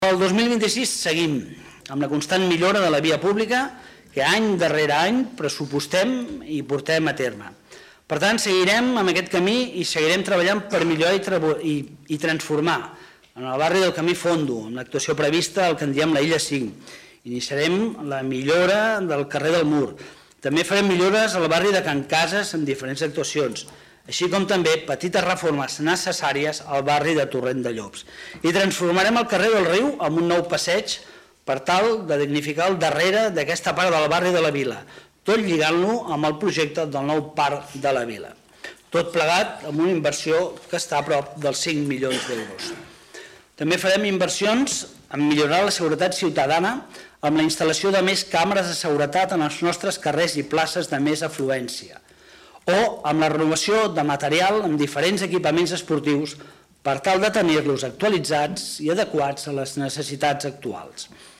Lluís Amat, regidor d'Hisenda i Règim Interior
Ple-Municipal-Desembre-02.-Lluis-Amat.mp3